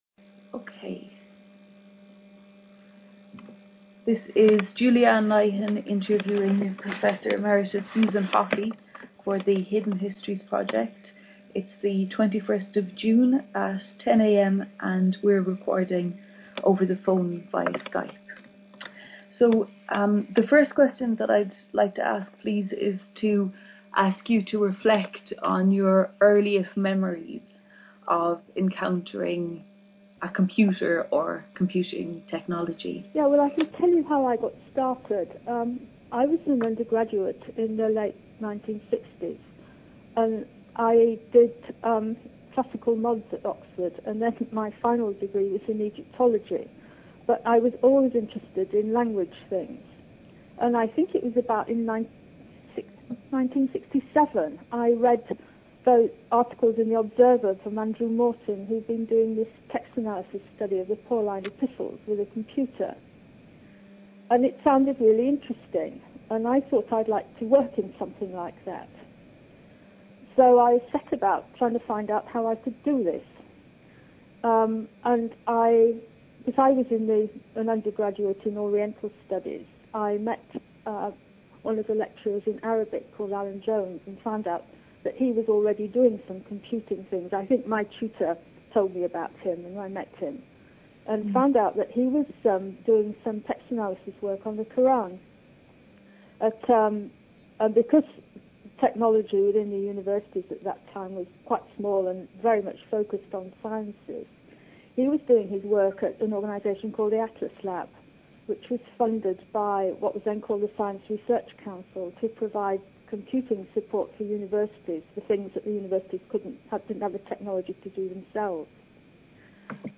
Phone call recorded via Pamela software for Skype
Interview